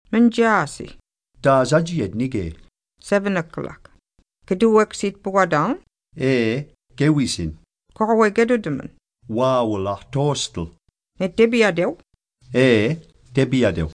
Here’s a transcription and translation of the conversation: Mnja’si!